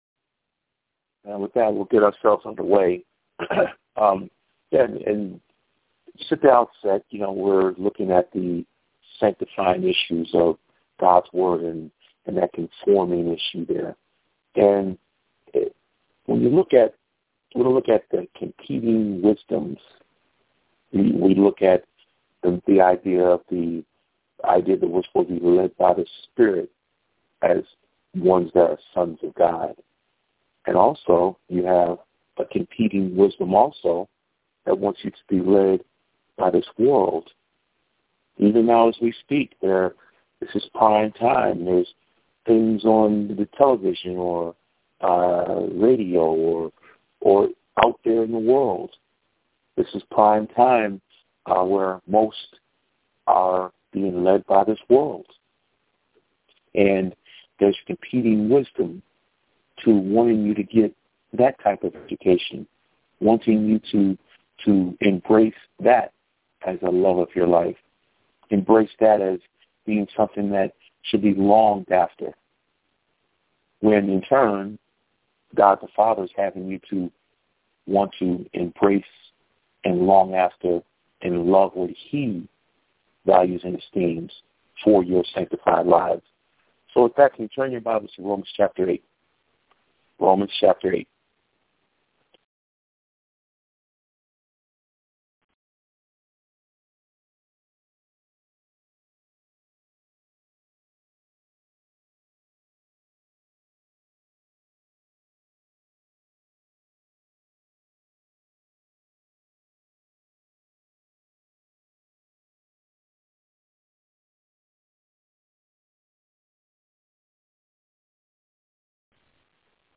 Weekly Phone Bible Studies